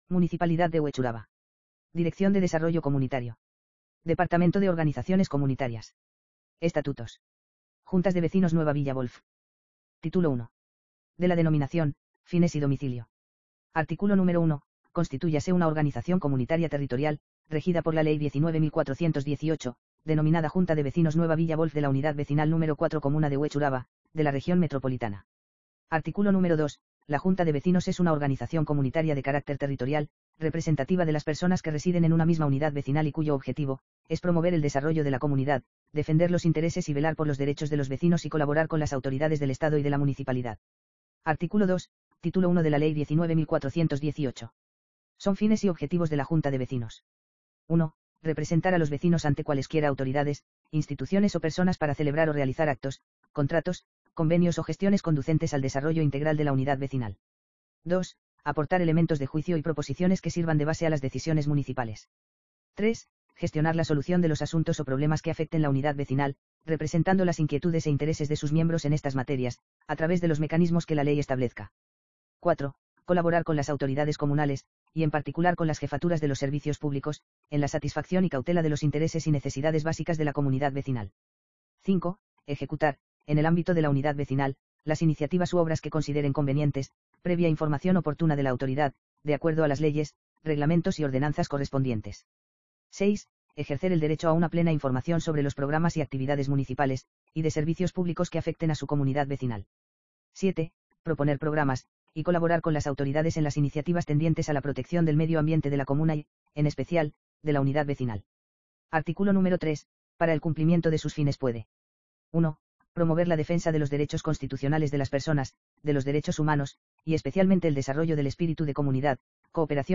lectura_r_Est-JV_NVW.mp3